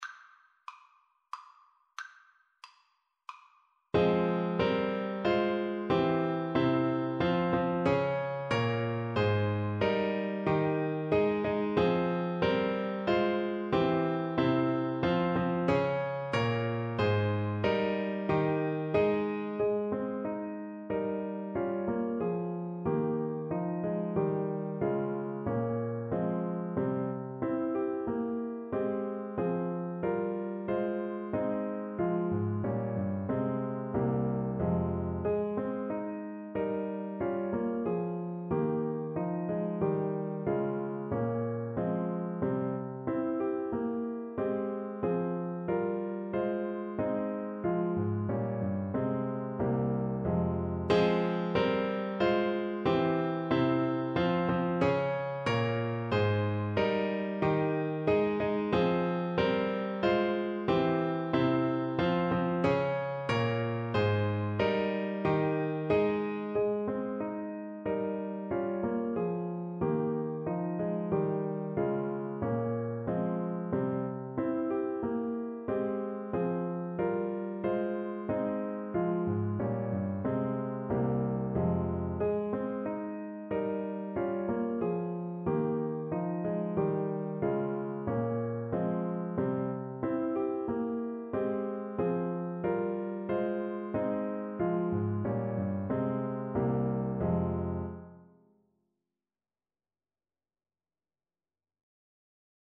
French Horn
Traditional Music of unknown author.
F minor (Sounding Pitch) C minor (French Horn in F) (View more F minor Music for French Horn )
3/2 (View more 3/2 Music)
Traditional (View more Traditional French Horn Music)